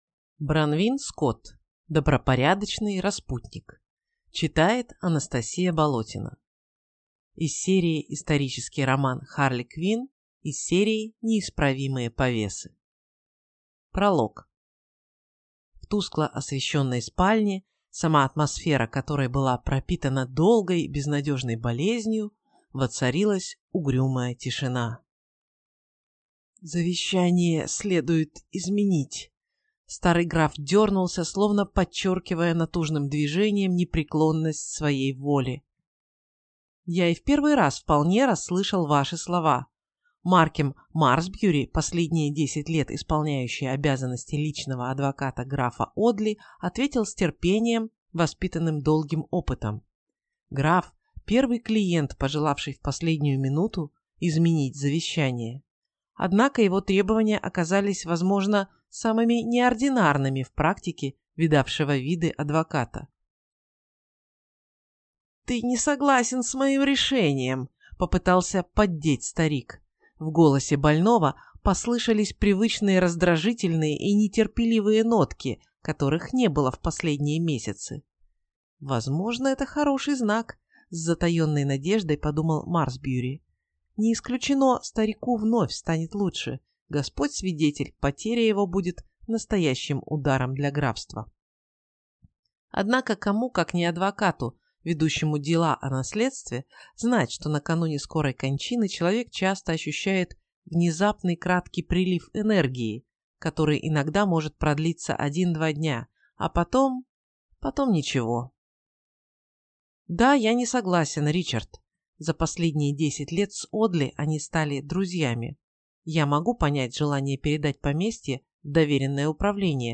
Аудиокнига Добропорядочный распутник | Библиотека аудиокниг